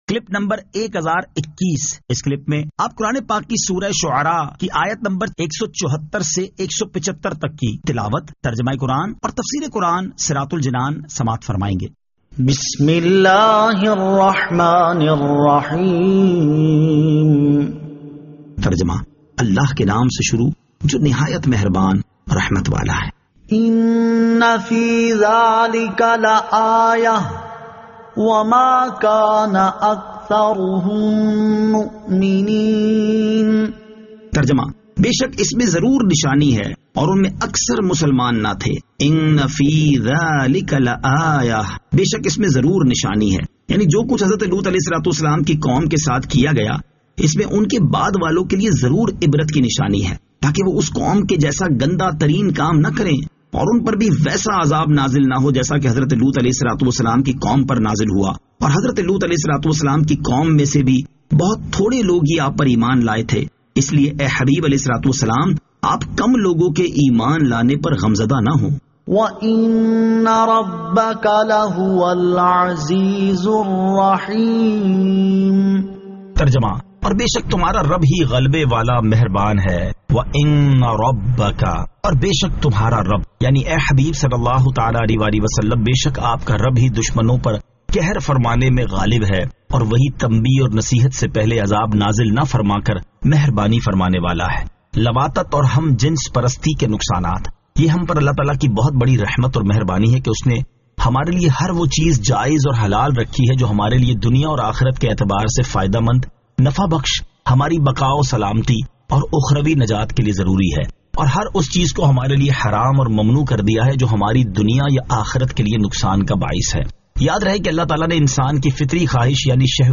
Surah Ash-Shu'ara 174 To 175 Tilawat , Tarjama , Tafseer